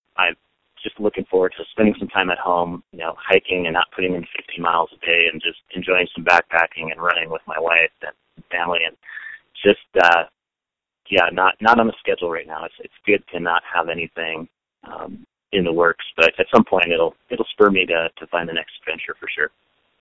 JUREK SAYING HE IS LOOKING FORWARD TO RECOVERING FROM THE HIKE.